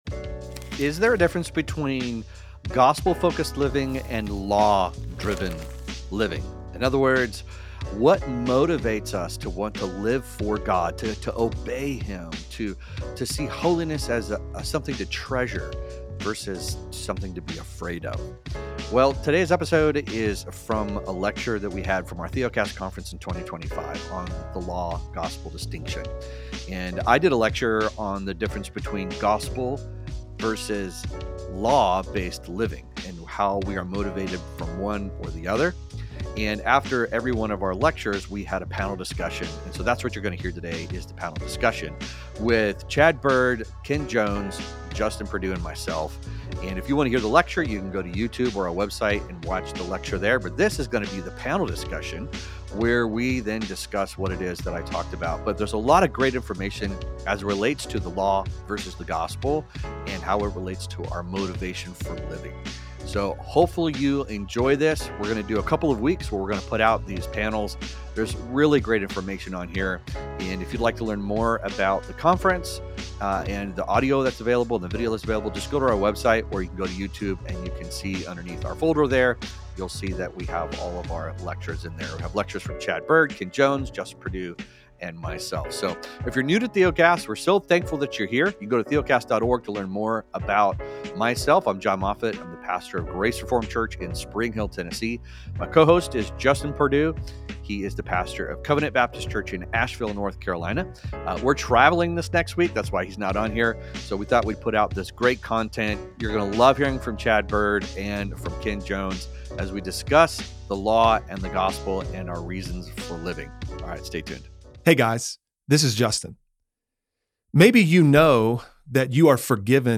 What truly motivates the Christian life: the gospel or the law? Too often, we confuse gospel-driven living with law-based performance. This panel discussion, recorded live at our 2025 Theocast Conference, explores how the distinction between law and gospel shapes our motivations, obedience, and joy in Christ.